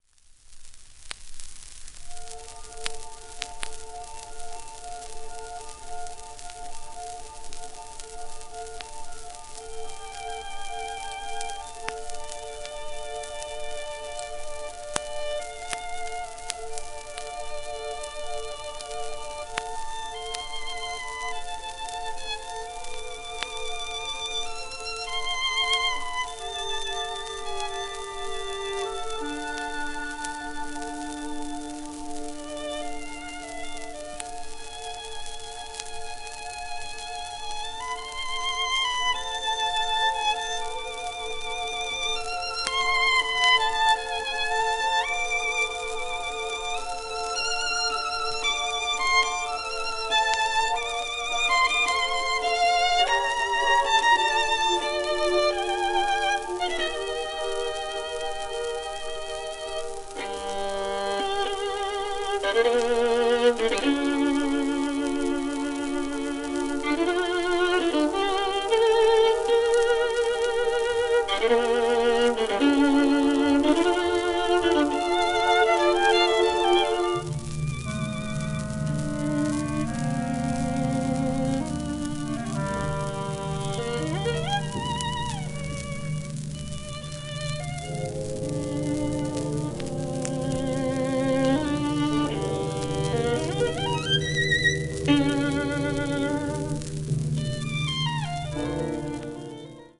盤質A- *１面目導入部小さなアタリ(音に出ない)、小キズ、薄い面擦れ,１枚目レーベルにシール
1945年11月21日ロンドン, アビー・ロードスタジオ録音